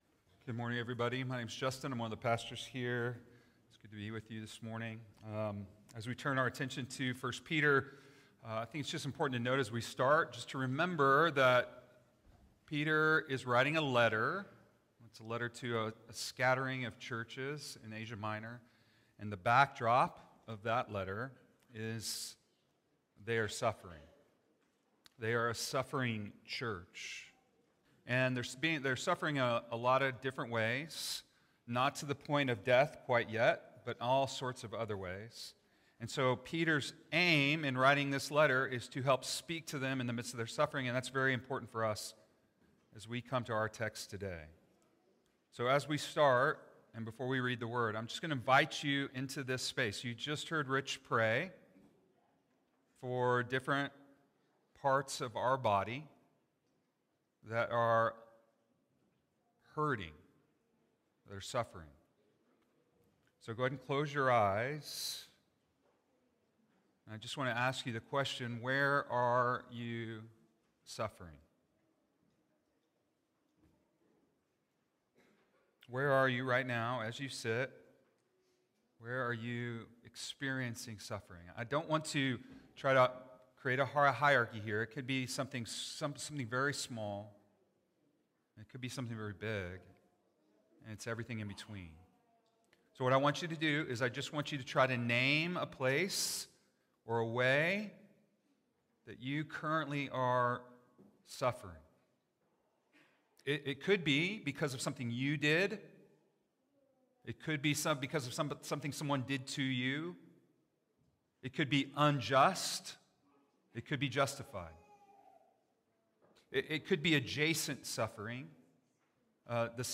11-9 sermon - Made with Clipchamp.m4a